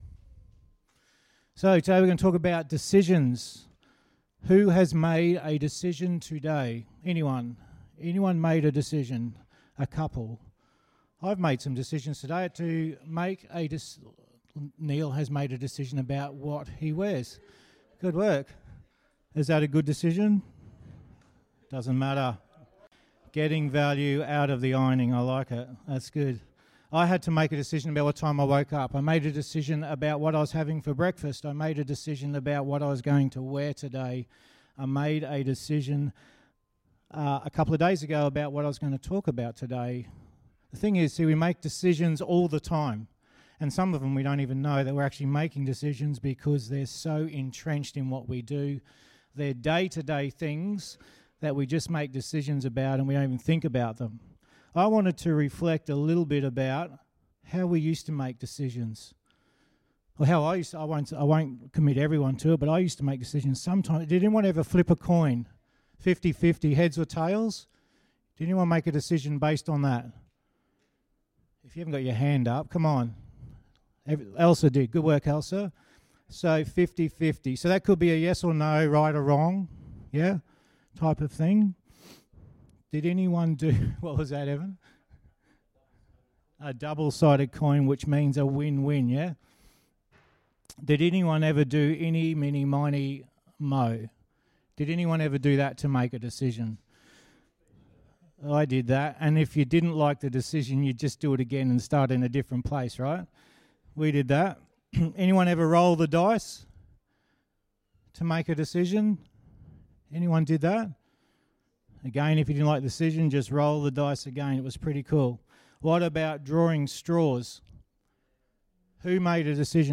Sermon 22.09.2019